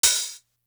Bang Bang Hat.wav